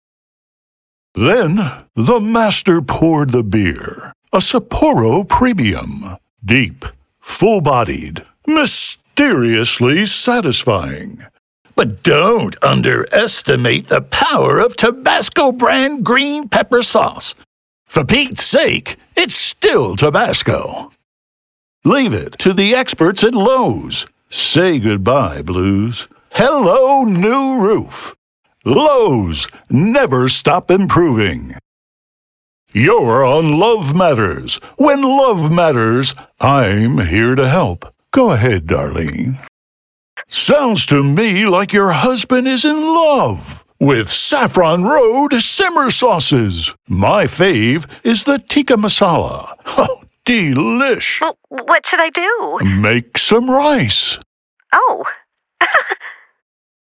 English - USA and Canada